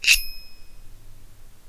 Knife.wav